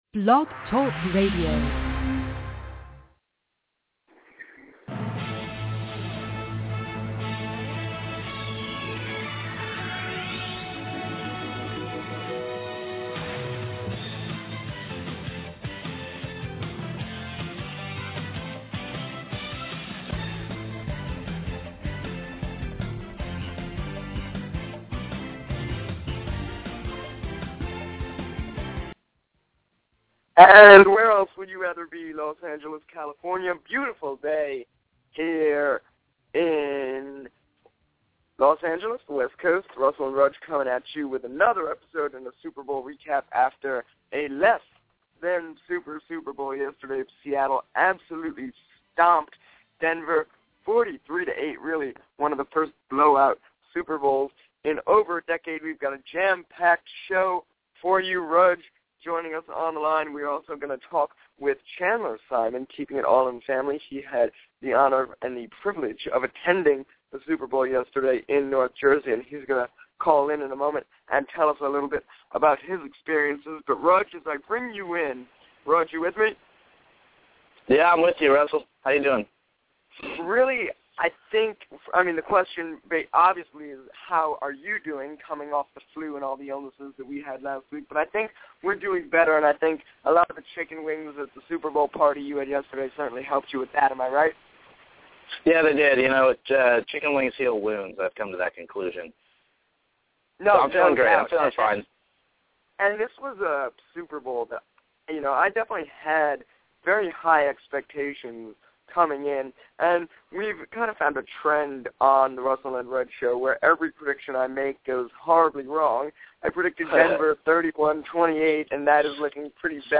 In this episode they interview a fan who attended the game, to get an inside scoop on what it was like to attend the first cold weather Super Bowl.